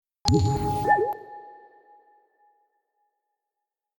Free SFX sound effect: Scan Accepted.
Scan Accepted
Scan Accepted.mp3